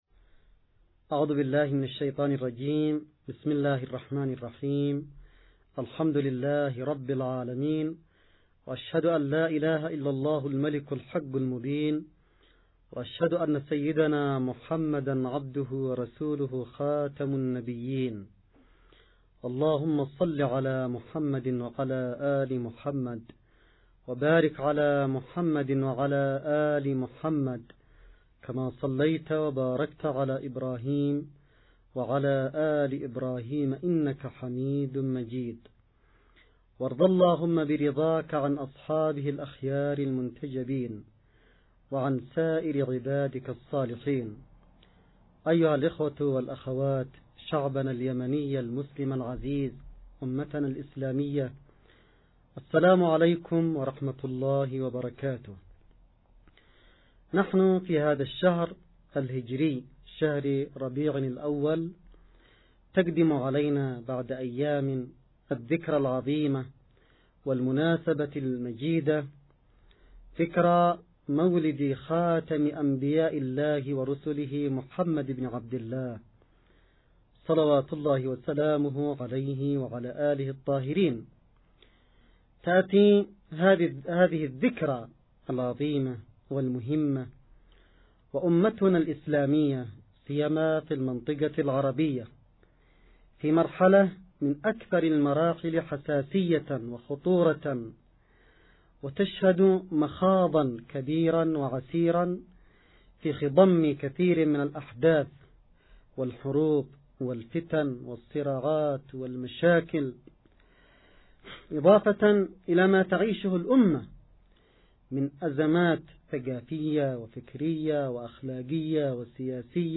نص + أستماع لمحاضرة السيد عبدالملك الحوثي المولد النبوي 7 ربيع اول 1439هـ – المحاضرة الأولى.